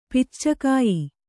♪ picca kāyi